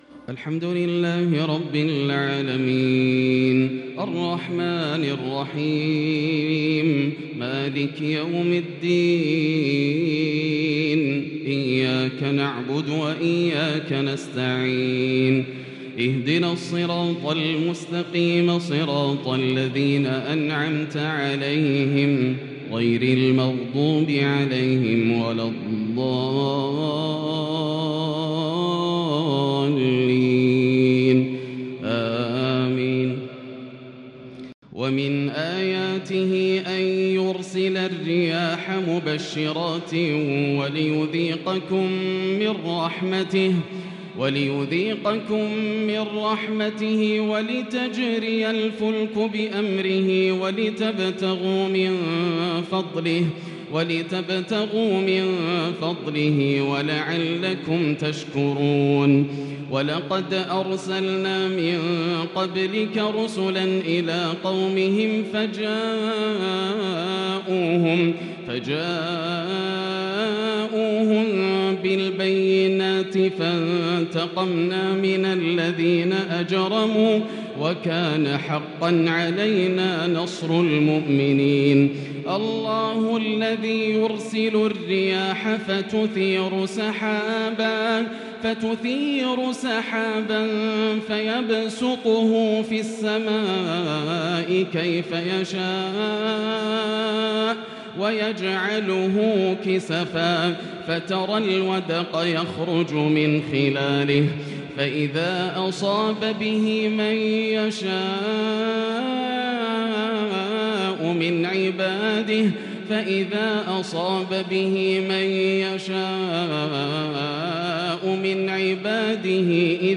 صلاة التراويح ليلة 24 رمضان 1442 l سورة الروم 46 _ سورة لقمان كاملة | taraweeh prayer The 24th night of Ramadan 1442H | from surah Ar-Rum and Luqman > تراويح الحرم المكي عام 1442 🕋 > التراويح - تلاوات الحرمين